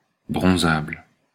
Ääntäminen
• IPA: /bʁɔ̃.zabl/